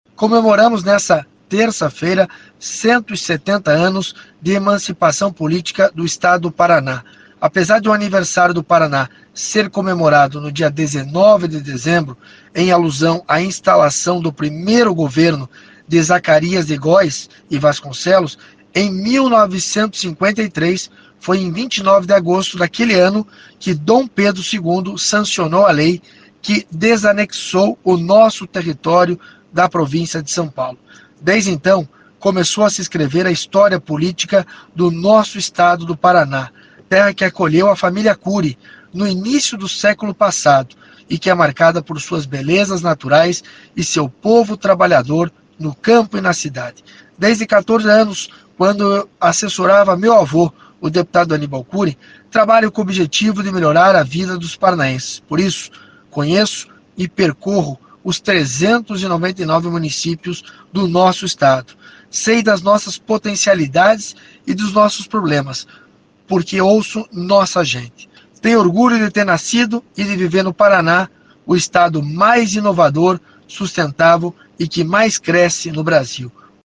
O deputado Estadual do Paraná, Alexandre Curi, fez um resumo dos últimos acontecimentos no Estado com exclusividade à Rádio Colmeia nessa quarta-feira, 30. Entre os assuntos estão a concessão do pedágio e o programa de pavimentação.
Você pode acompanhar a fala do deputado nos áudios abaixo.